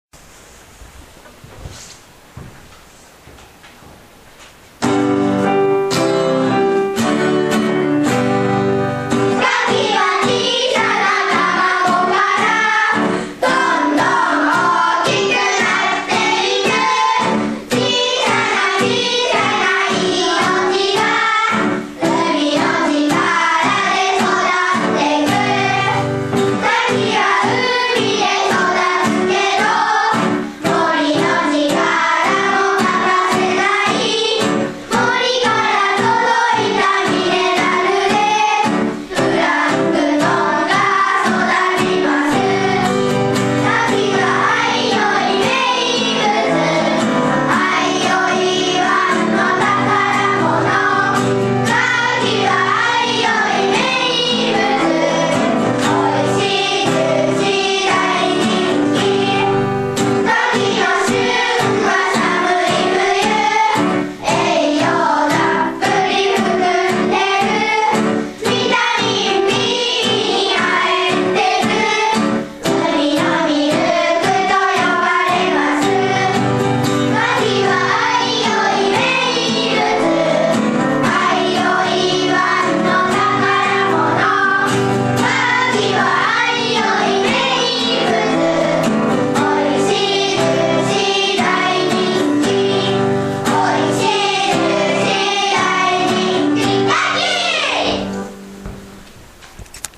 相生小学校3年生が環境学習の発表会で、「かきの歌」を披露した。
すごく、わかりやすく、気持ちのこもったいい歌だ。まだまだ歌いこんでないみたいだけど、そこが子どもらしくておもしろい。